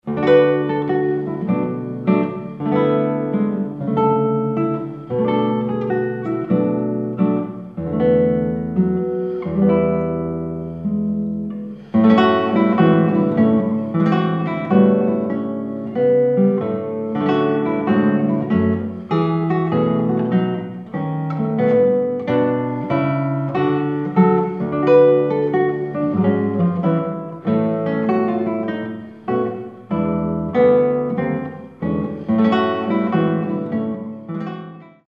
solo guitar
The recording quality is rich and resonant